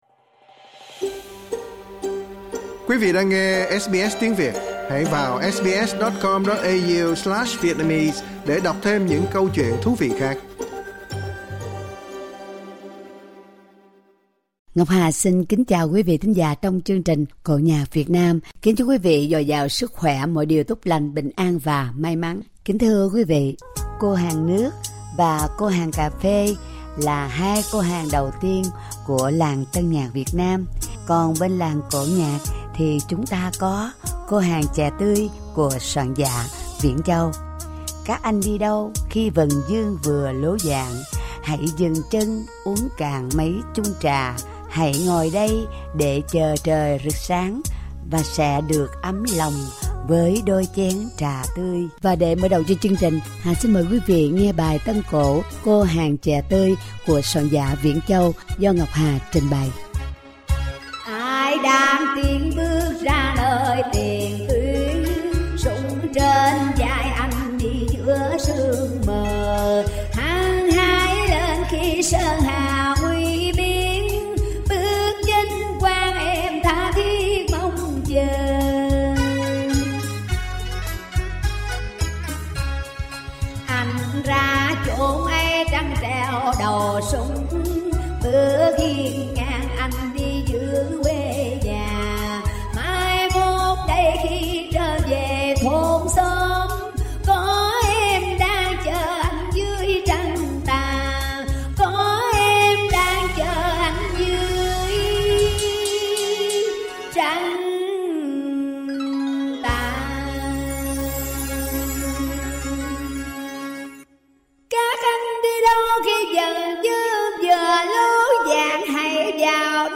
tân cổ